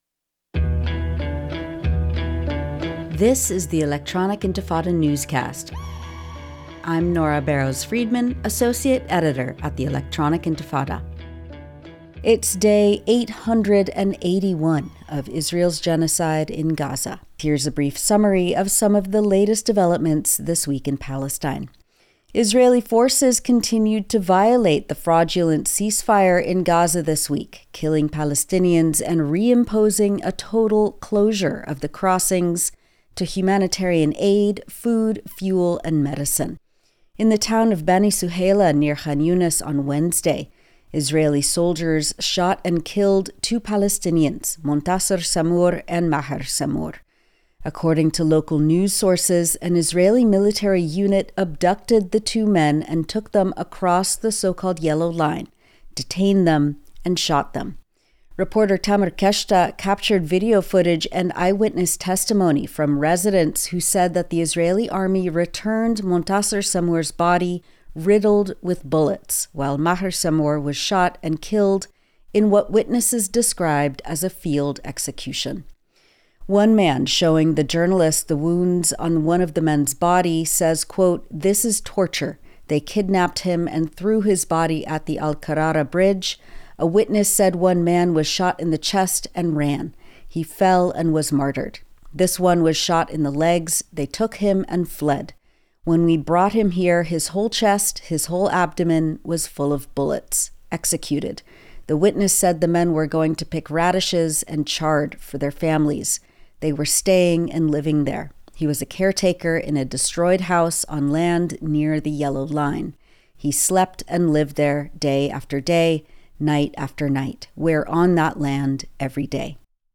Weekly Program